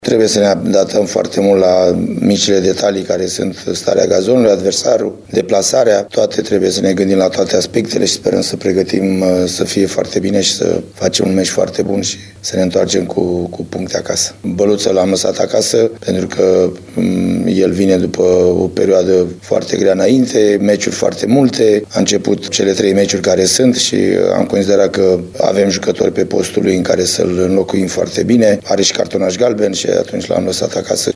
Farul nu are probleme de lot înaintea jocului de mâine, de la Arad. Cu toate acestea, va fi menajat Tudor Băluță, o măsură explicată de Gheorghe Hagi: